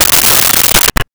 Gorilla Snarl 01
Gorilla Snarl 01.wav